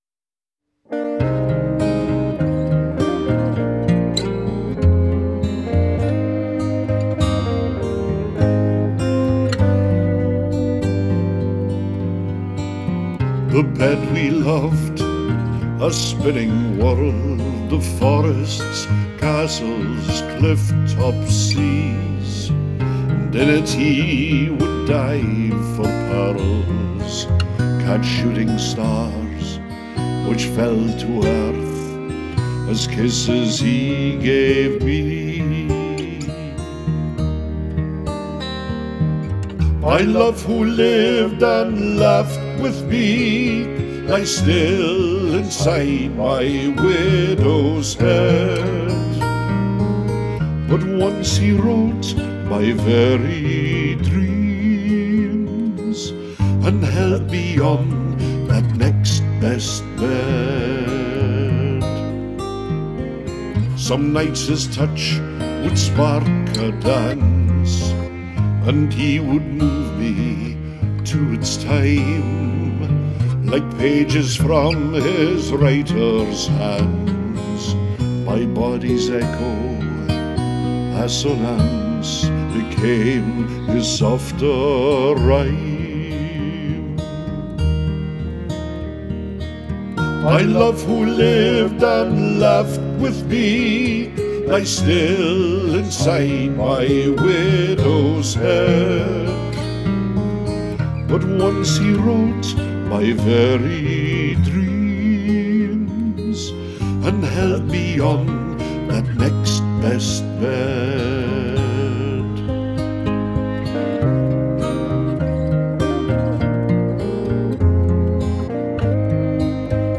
Independent Scottish Songwriter, Singer, and Recording Artist
Traditional